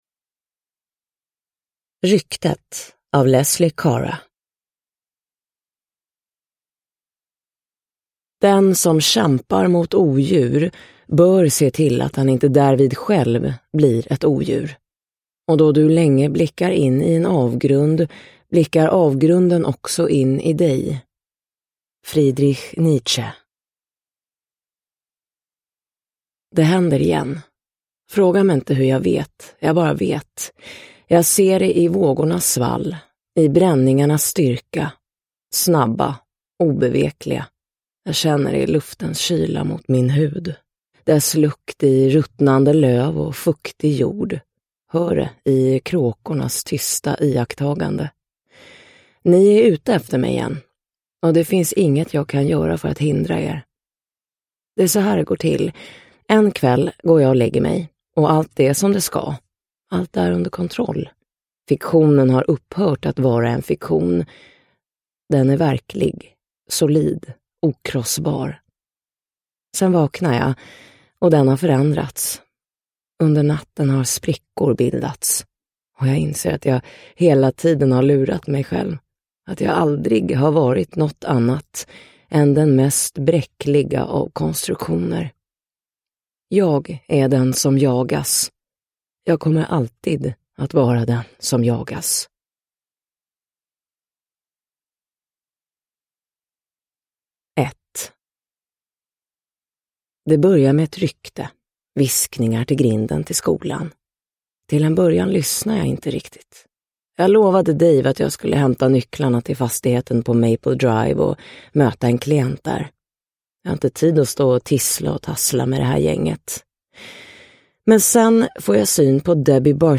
Ryktet – Ljudbok – Laddas ner